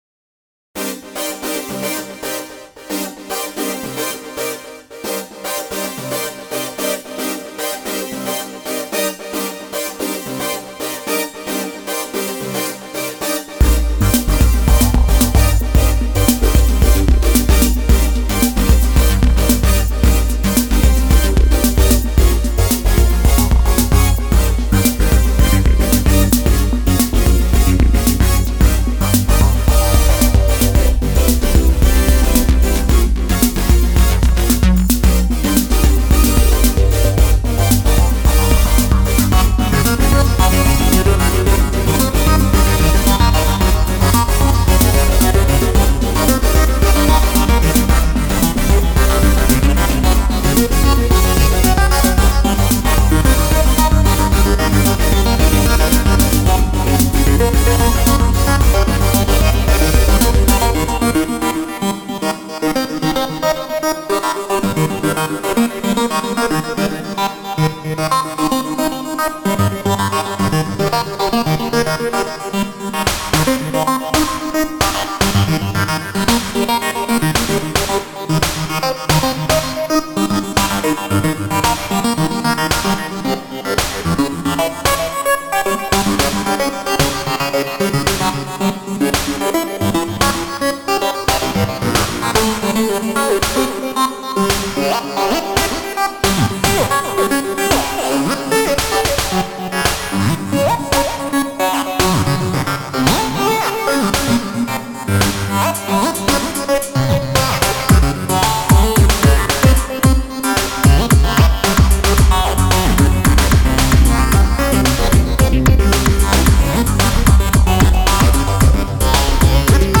YouTube Live session with MIDIbox SEQ V3 (the stereo MP3 version is